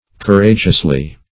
\Cour*a"geous*ly\